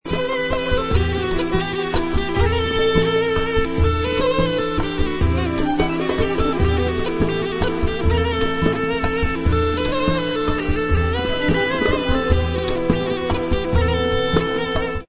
nouv. musique trad.